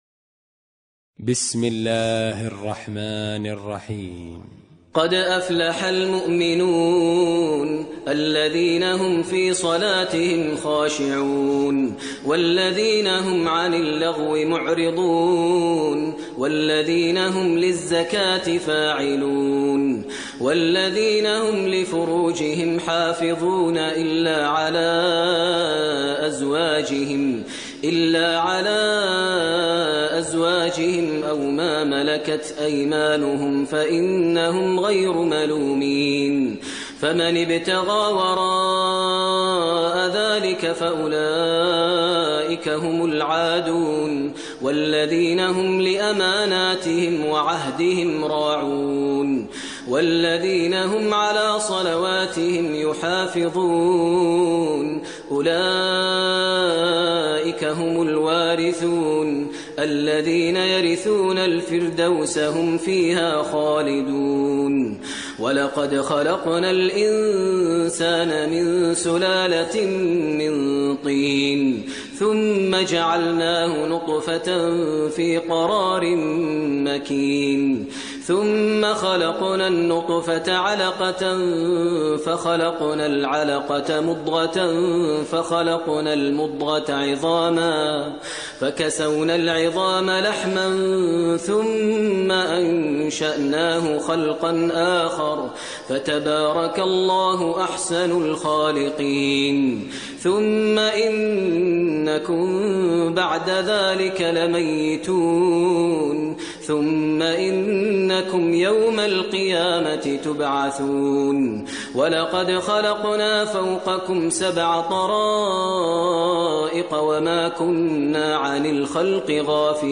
ترتیل سوره مؤمنون با صدای ماهر المعیقلی
023-Maher-Al-Muaiqly-Surah-Al-Mumenoon.mp3